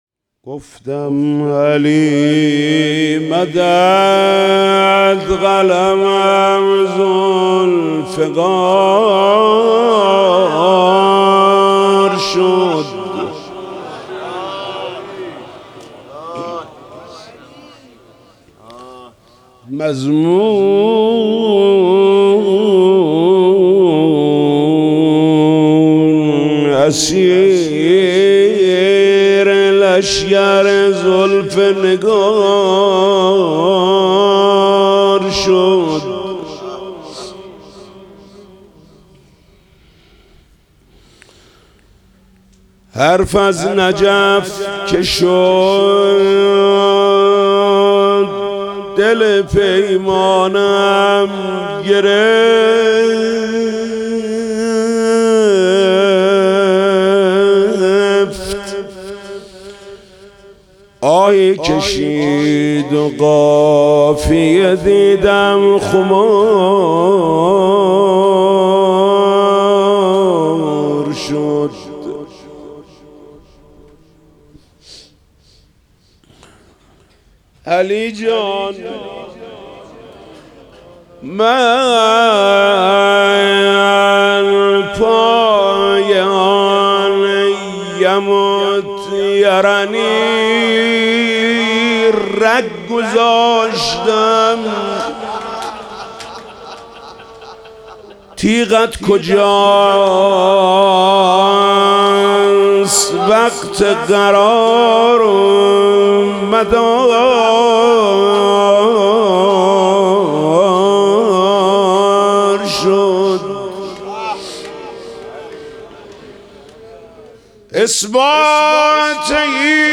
گفتم علی مدد قلمم ذوالفقار شد - مدح شب پنجم فاطمیه 1403